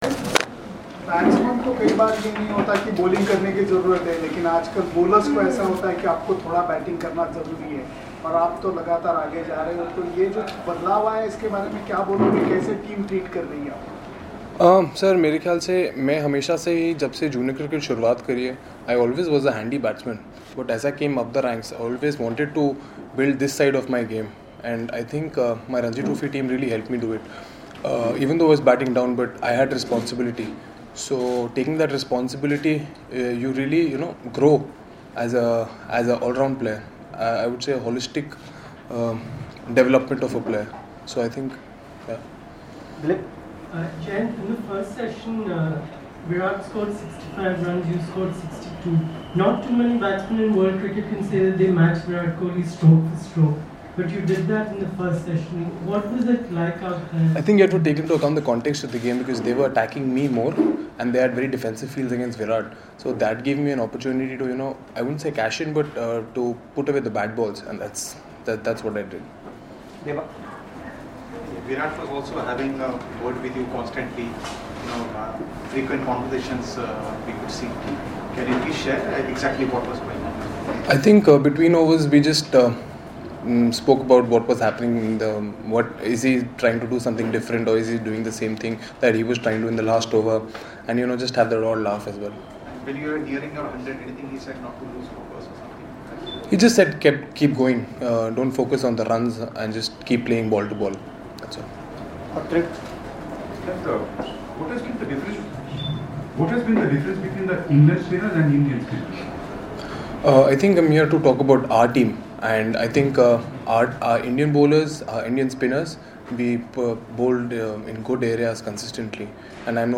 LISTEN: Jayant Yadav speaks on his maiden Test ton